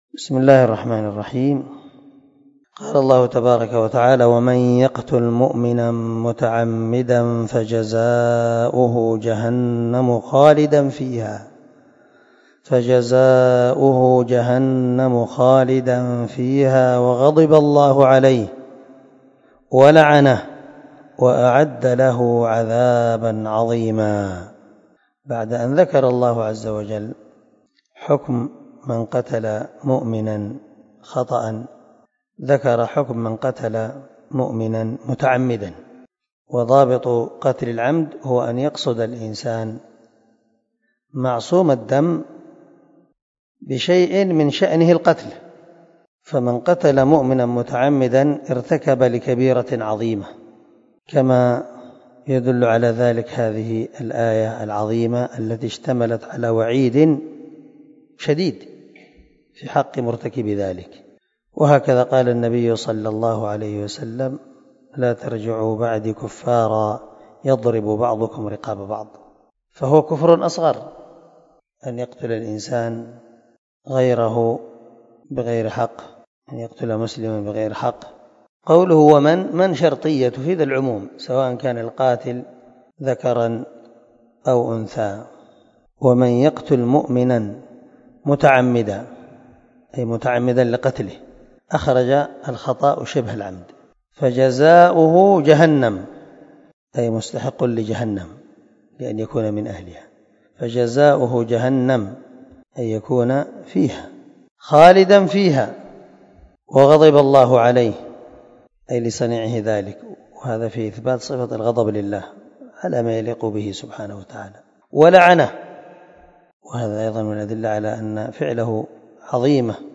293الدرس 61 تفسير آية ( 93 ) من سورة النساء من تفسير القران الكريم مع قراءة لتفسير السعدي
دار الحديث- المَحاوِلة- الصبيحة.